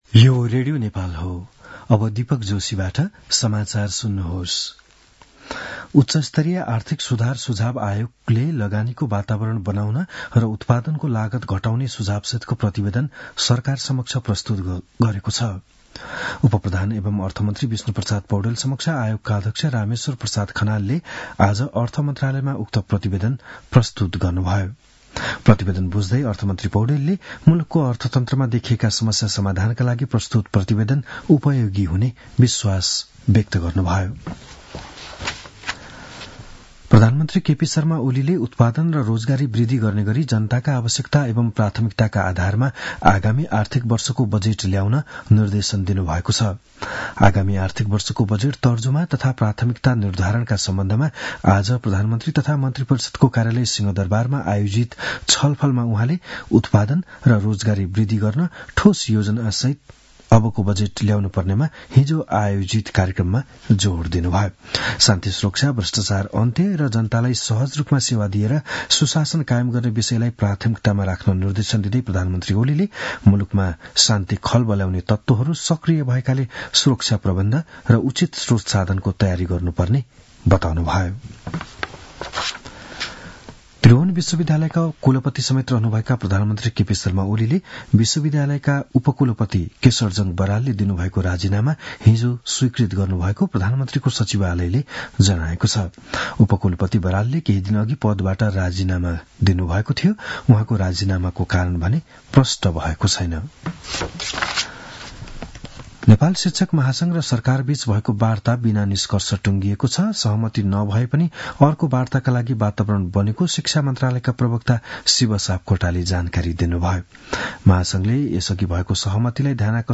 बिहान ११ बजेको नेपाली समाचार : २९ चैत , २०८१
11-am-news-1-4.mp3